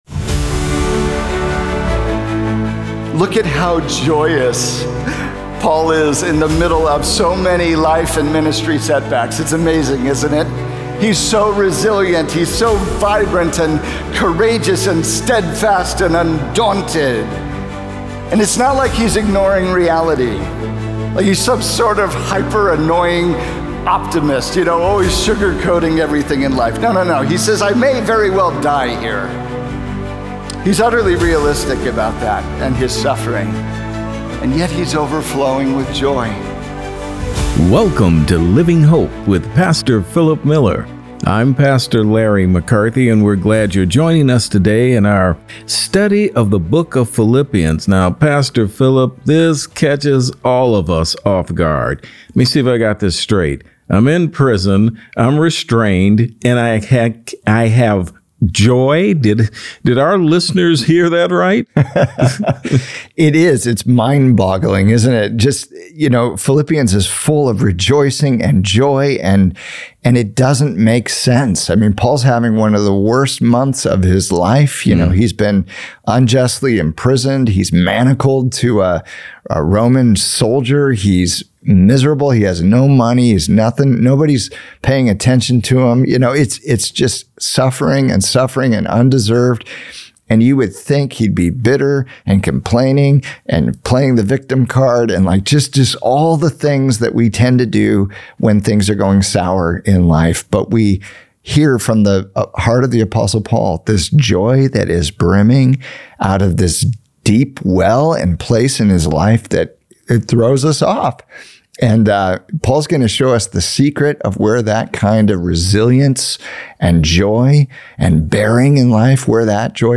Finding Unstoppable Joy in a Broken World | Radio Programs | Living Hope | Moody Church Media